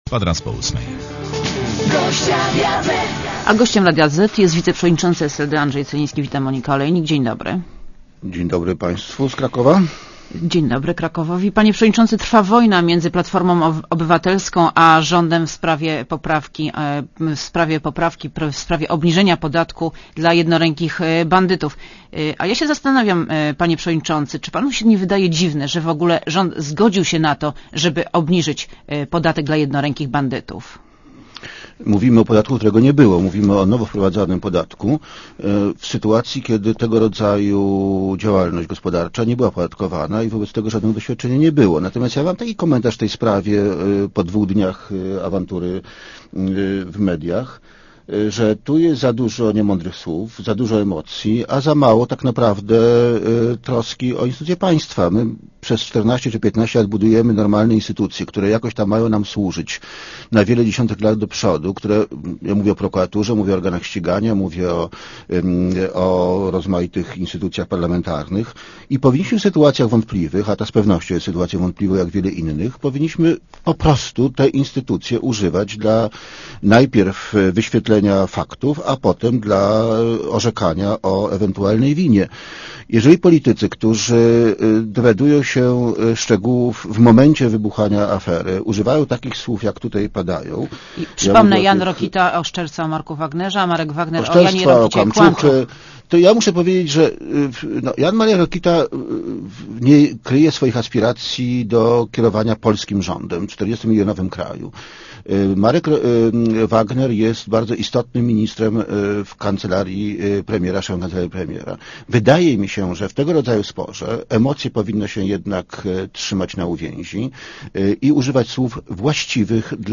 celinskiwywiad.mp3